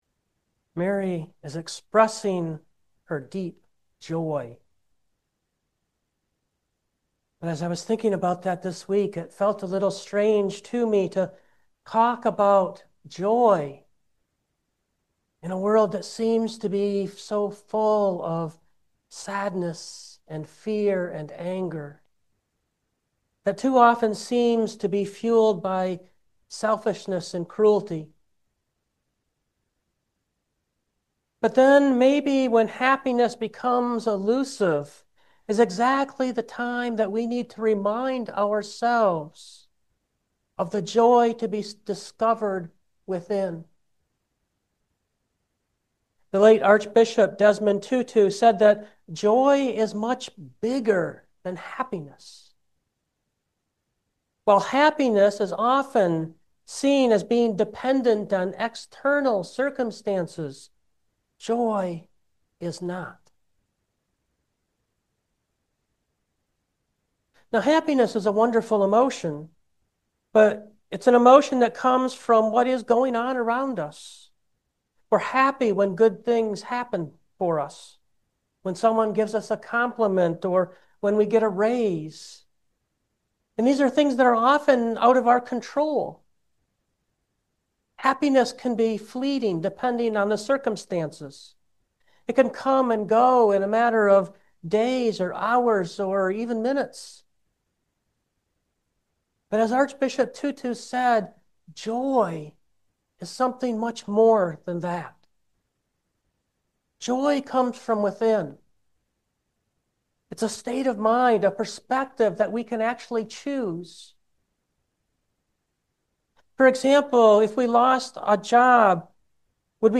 2025 Magnification of the Soul Preacher